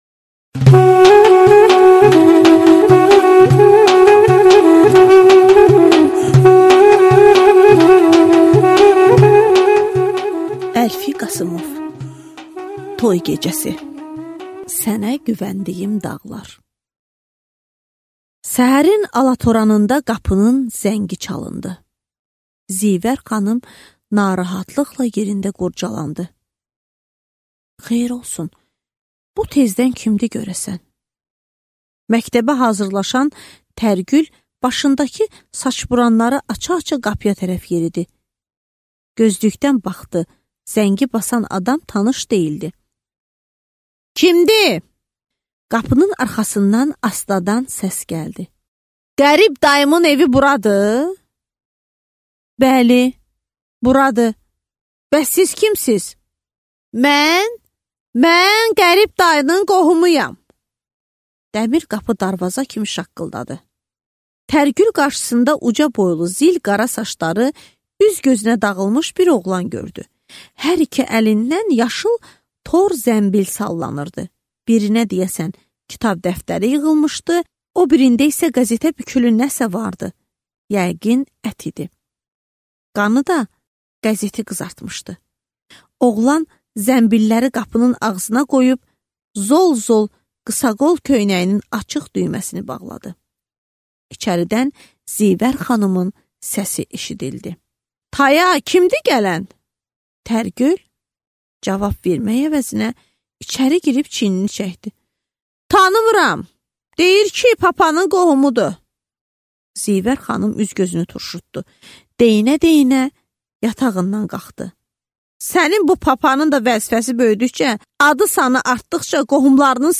Аудиокнига Toy gecəsi | Библиотека аудиокниг